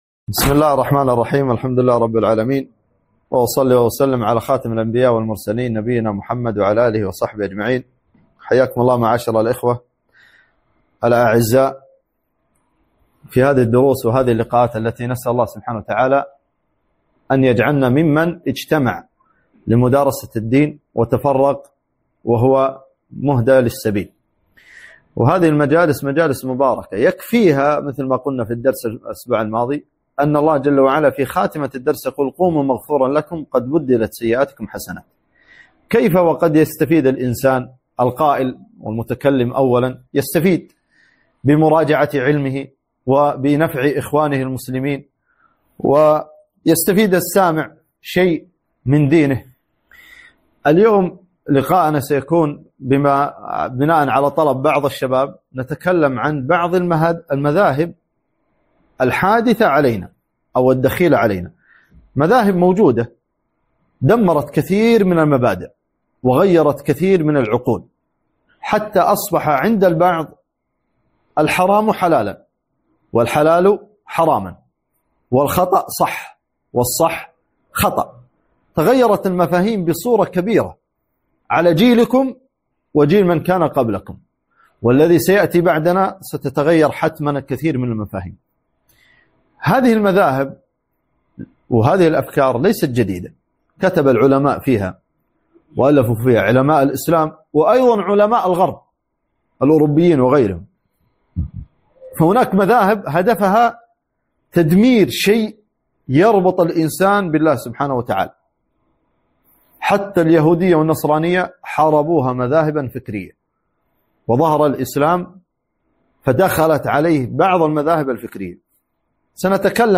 محاضرة - حول بعض المذاهب الفكرية